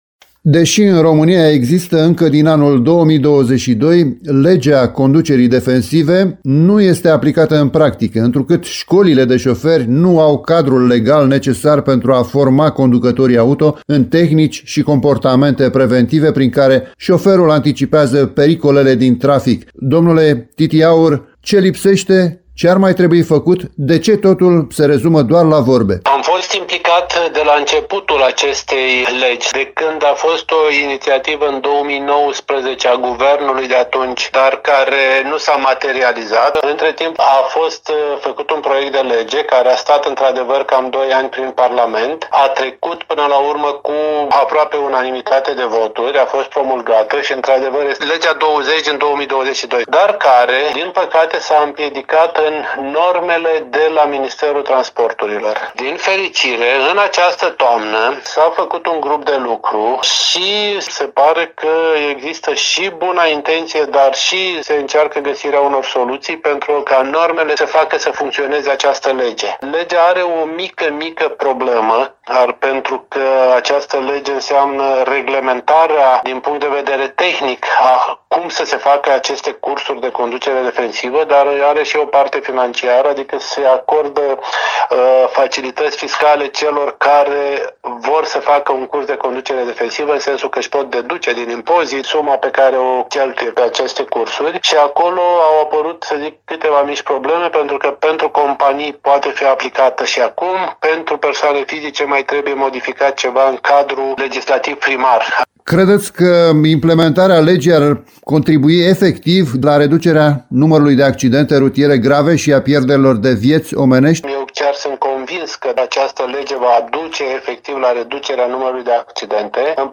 a dialogat pe această temă cu
pilot și instructor auto.